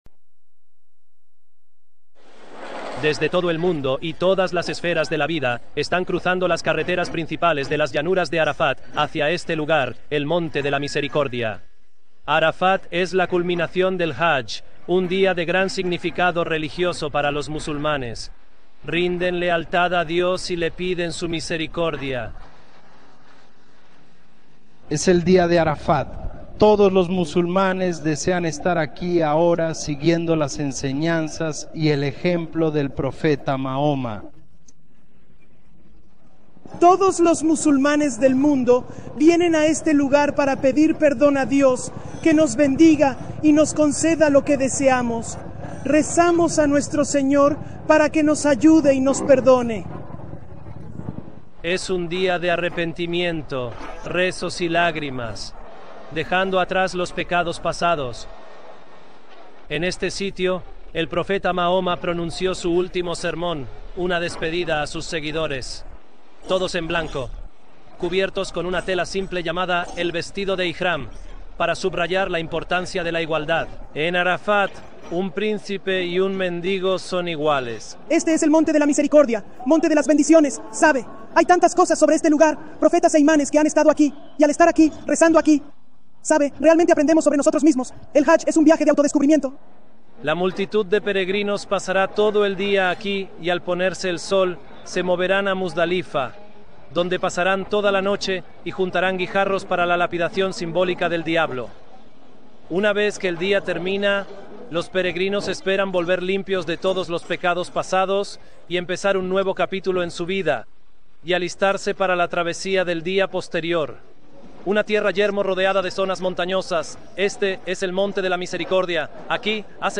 Descripción: Este video es una cobertura informativa de Al-Jazeera sobre la virtud del Hajj.